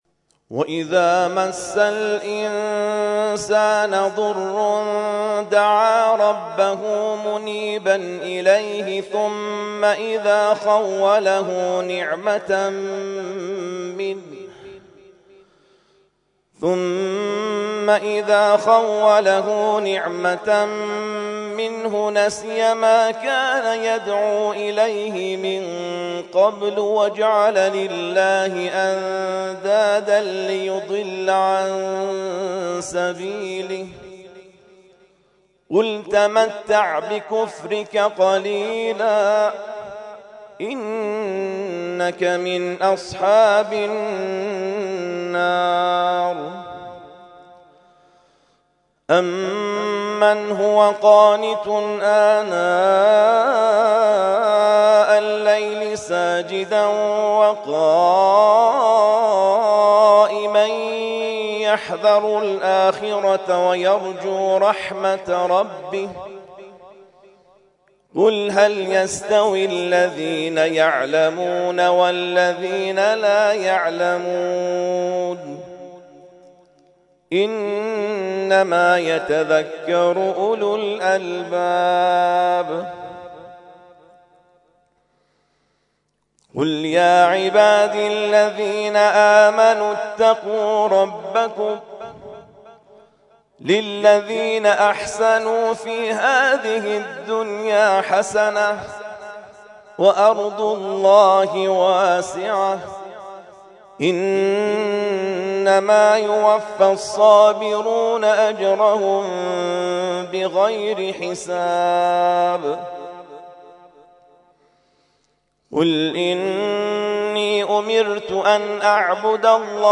ترتیل خوانی جزء ۲۳ قرآن کریم در سال ۱۳۹۵